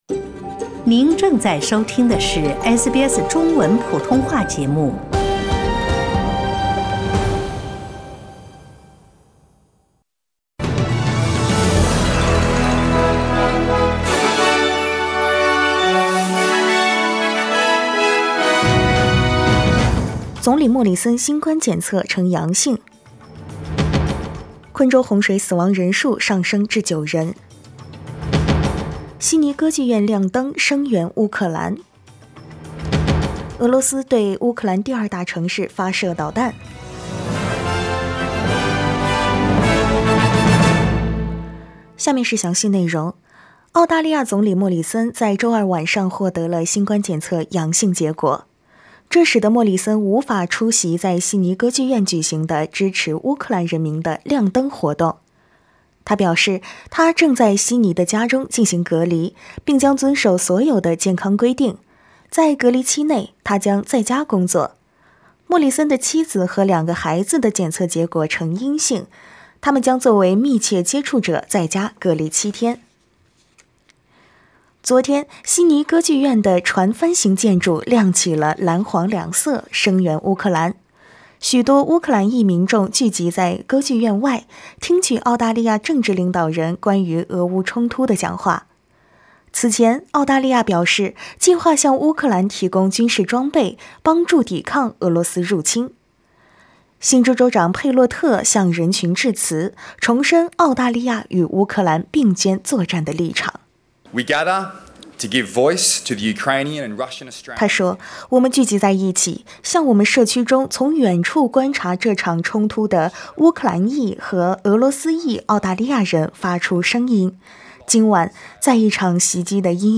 SBS早新闻（3月2日）
SBS Mandarin morning news Source: Getty Images